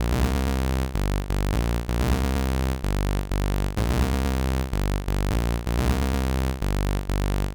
• Electric Techno Bass.wav
Electric_Techno_Bass__CiJ.wav